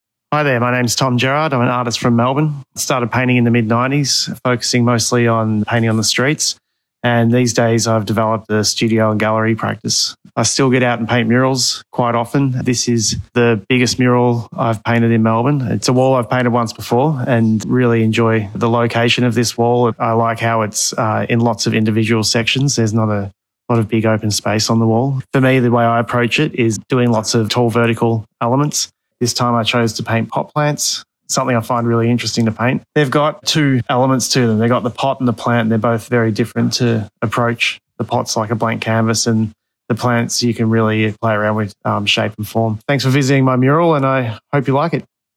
Listen to the artist